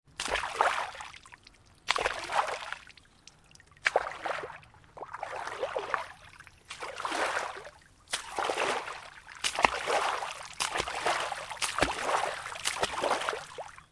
جلوه های صوتی
دانلود آهنگ آب 39 از افکت صوتی طبیعت و محیط
دانلود صدای آب 39 از ساعد نیوز با لینک مستقیم و کیفیت بالا